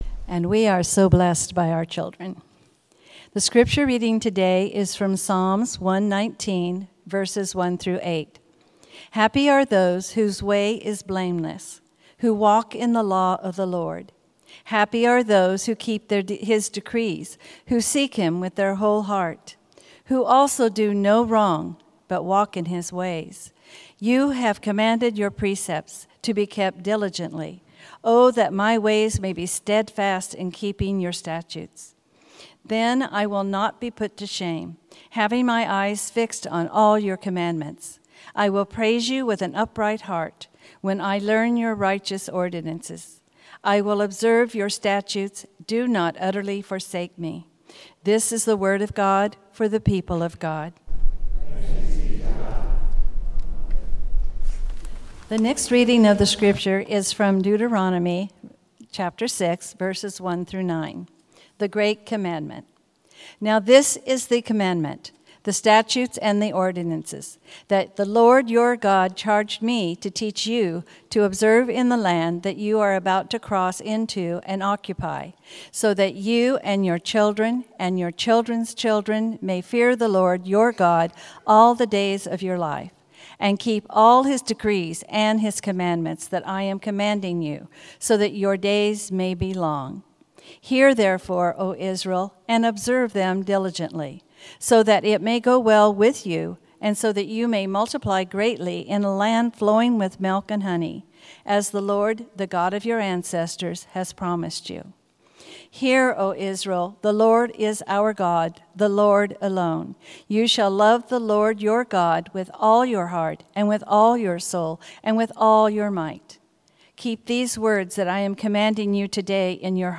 Sermon – Methodist Church Riverside
Twenty-Fifth Sunday after Pentecost sermon